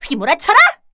assassin_w_voc_artfullchaser02.ogg